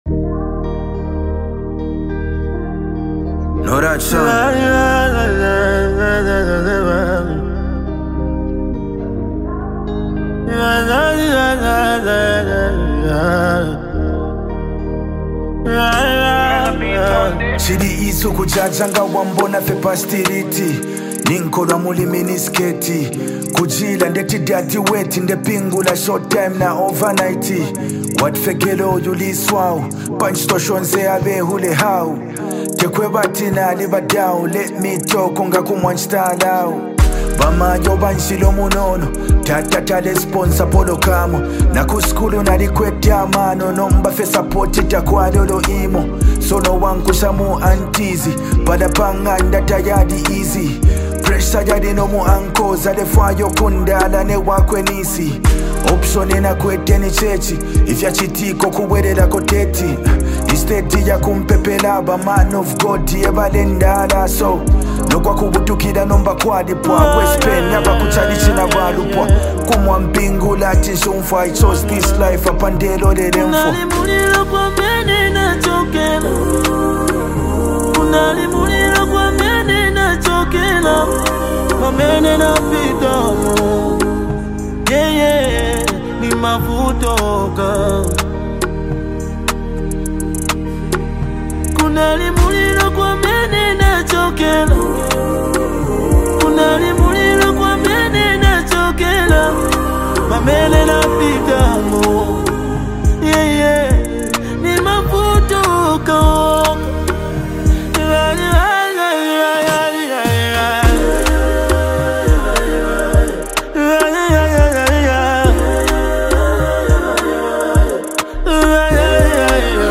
With its captivating beats and seamless flow